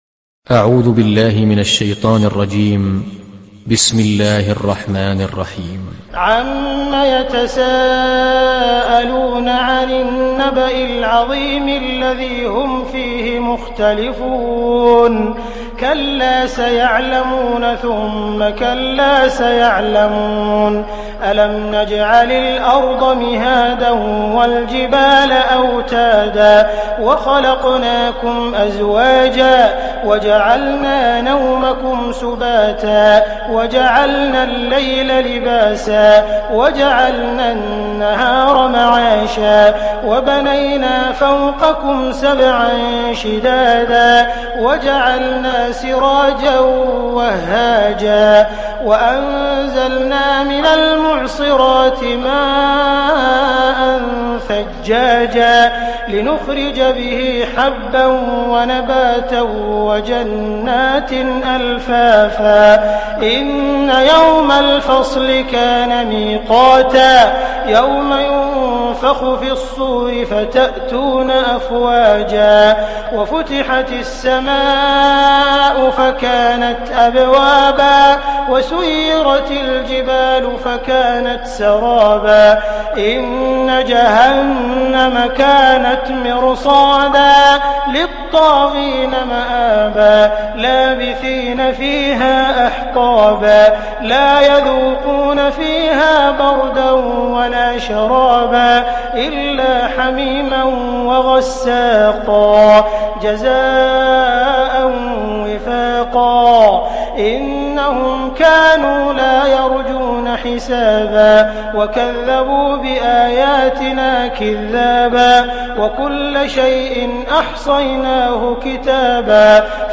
Surat An Naba Download mp3 Abdul Rahman Al Sudais Riwayat Hafs dari Asim, Download Quran dan mendengarkan mp3 tautan langsung penuh